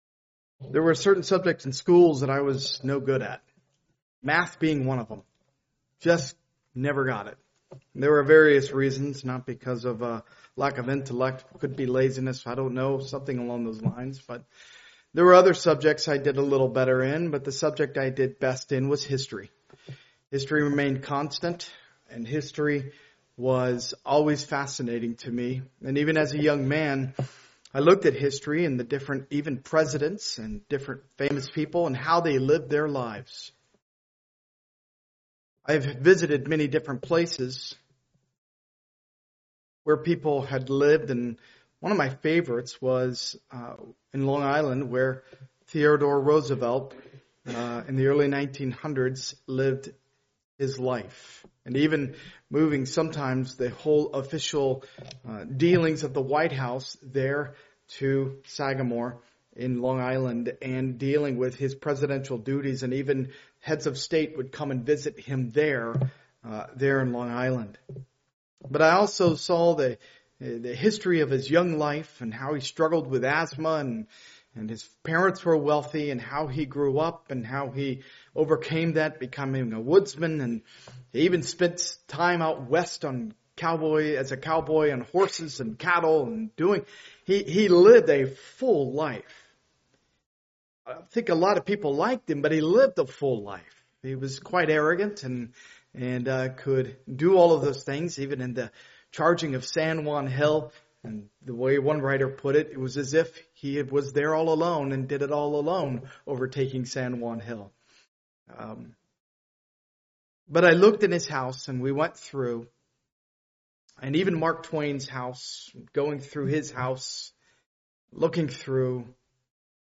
Part of the Topical series, preached at a Morning Service service.